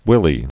(wĭlē)